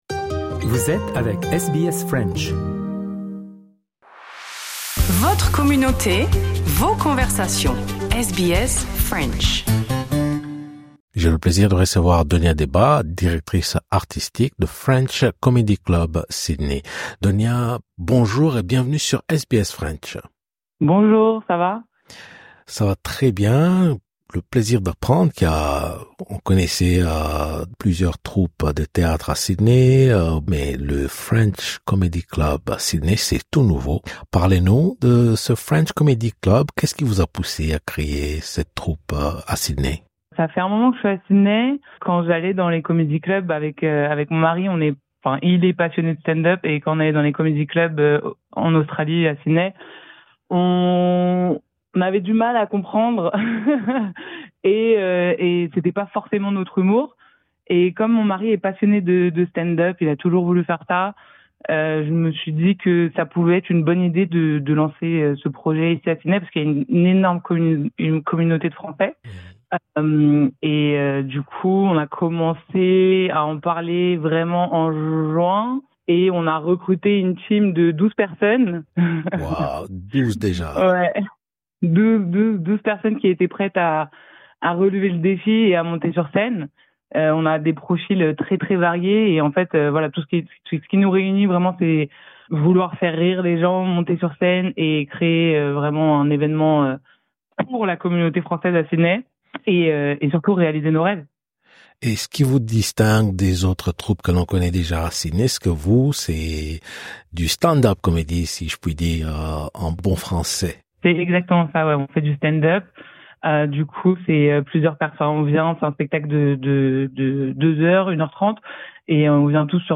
Dans un entretien avec SBS French